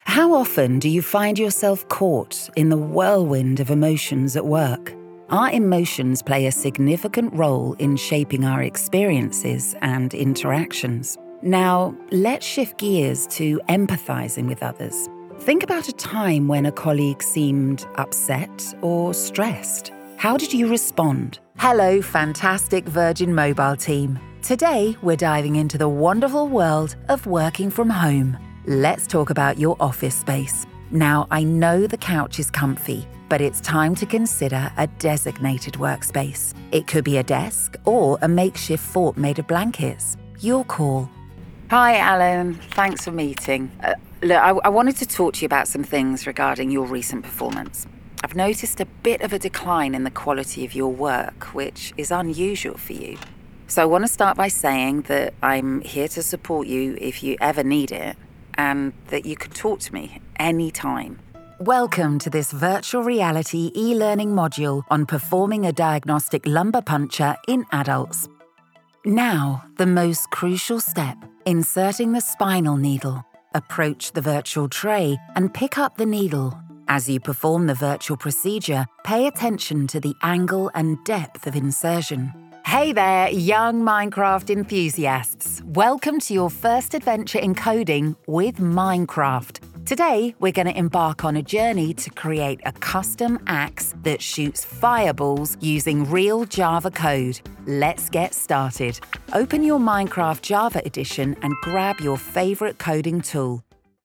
Multi-Nominated Professional British Voiceover. Clear, Contemporary, Confident.
e-Learning Reel
Natural RP accent, can also voice Neutral/International and character.
Broadcast-ready home studio working with a Neumann TLM 103 mic.